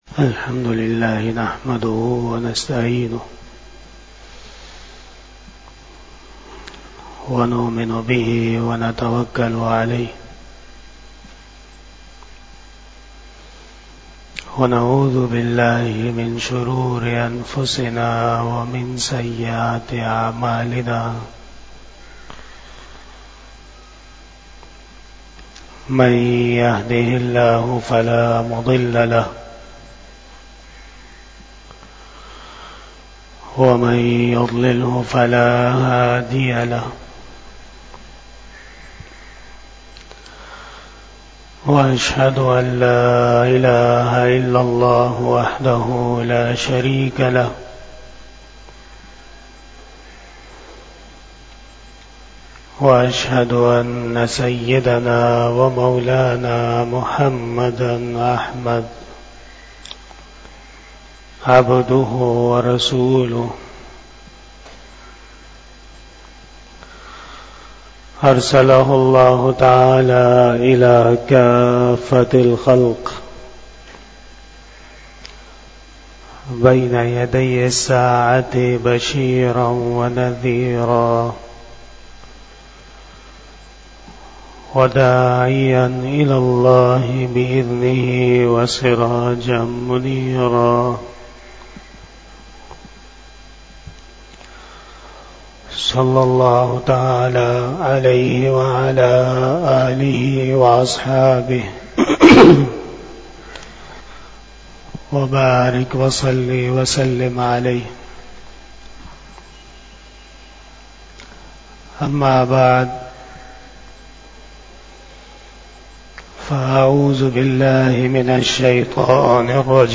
48 Bayan E Jummah 01 December 2023 (16 Jamadi Oula 1445 HJ)